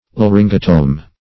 Meaning of laryngotome. laryngotome synonyms, pronunciation, spelling and more from Free Dictionary.
Search Result for " laryngotome" : The Collaborative International Dictionary of English v.0.48: Laryngotome \La*ryn"go*tome\, n. (Surg.)